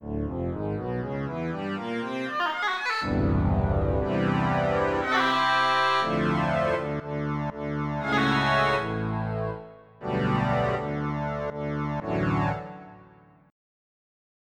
bagpipe.sf2